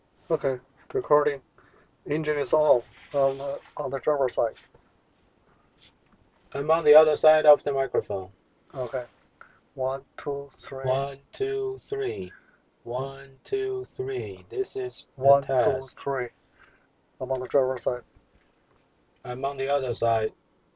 Demo – In-Vehicle Voice Separation:
CrispMic has stereo output, where the Left channel is Speaker 1’s voice and the Right channel is Speaker 2’s voice. Background noise is cancelled in real time:
Audio-2_CrispMic_Two_Speakers_cut.wav